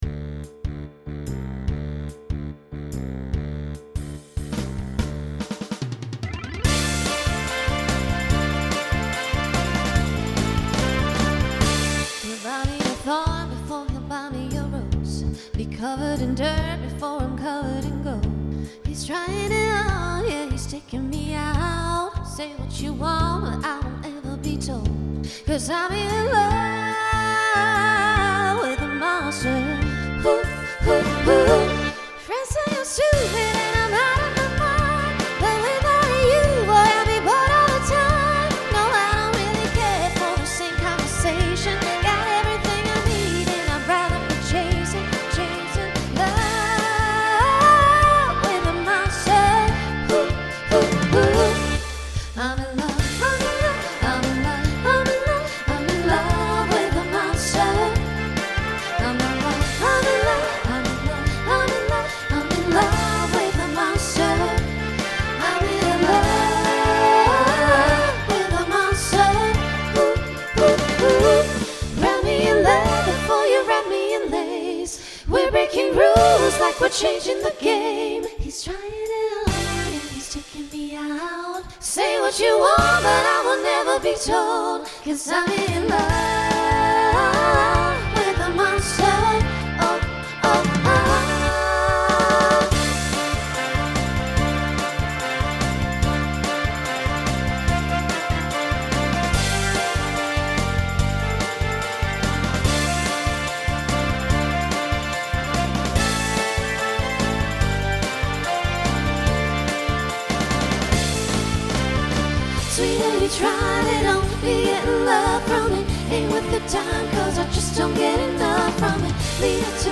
Voicing SSA Instrumental combo Genre Pop/Dance
Mid-tempo